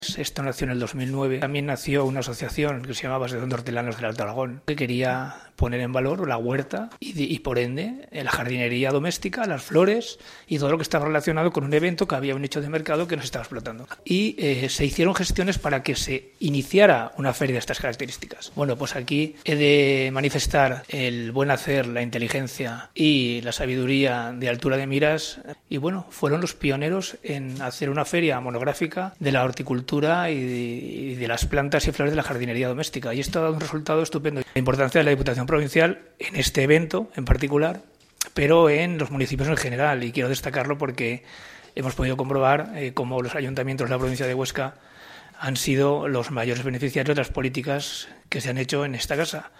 Audio del diputado Javier Betorz:
DPH_JavierBetorz_FeriaPrimaveraBiescas_mayo2019.mp3